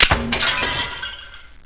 PSP/CTR: Also make weapon and zombie sounds 8bit
clipoff.wav